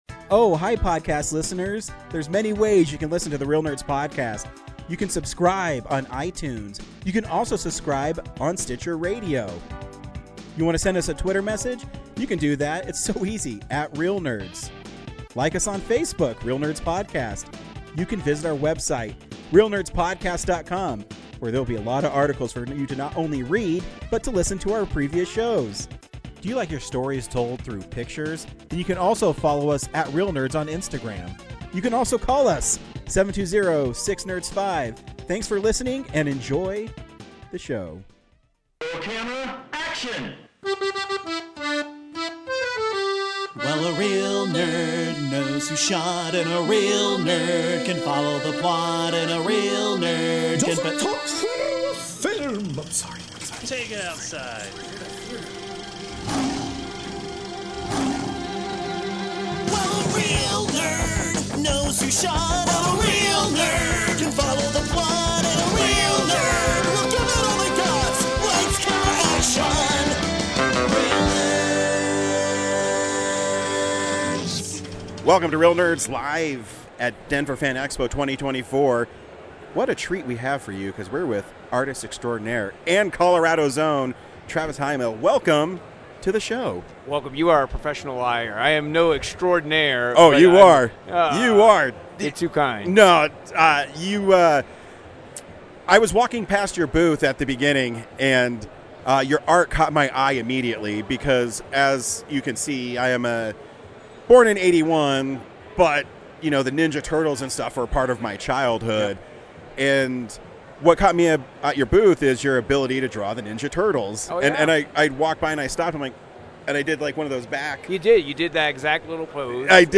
by Reel Nerds Podcast | Denver FanExpo 2024